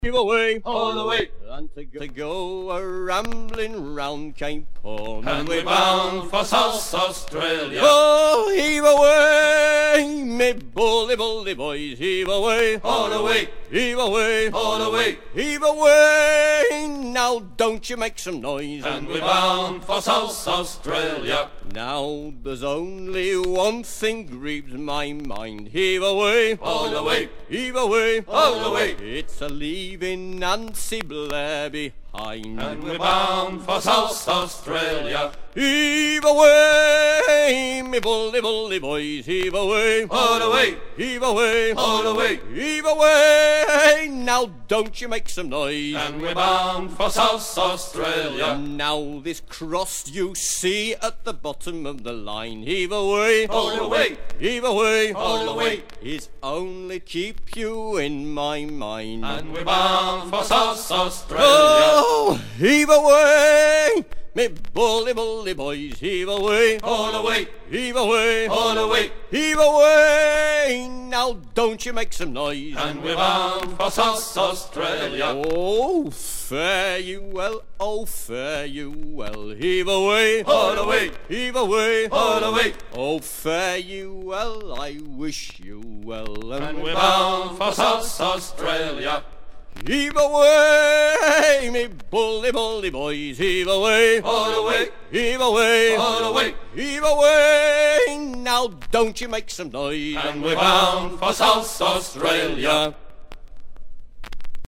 Chantey anglais
Fonction d'après l'analyste gestuel : à pomper
Usage d'après l'analyste circonstance : maritimes
Pièce musicale éditée